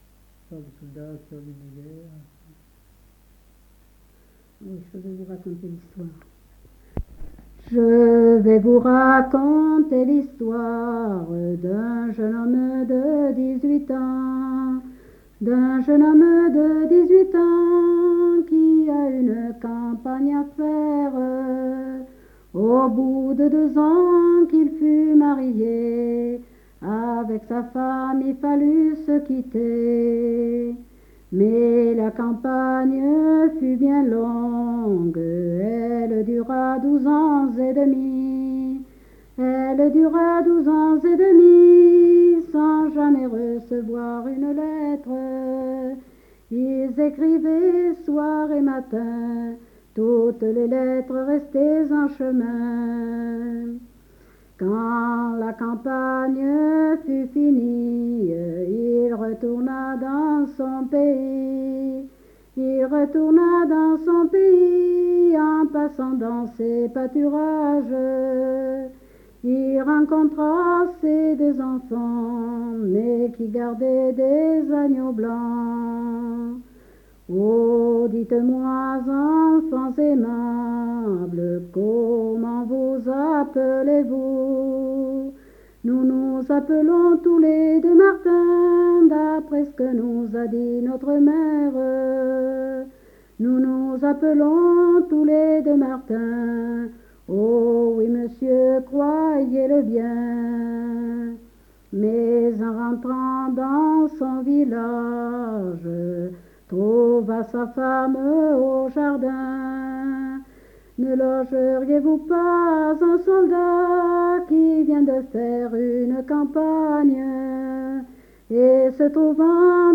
Aire culturelle : Viadène
Lieu : Saint-Amans-des-Cots
Genre : chant
Effectif : 1
Type de voix : voix de femme
Production du son : chanté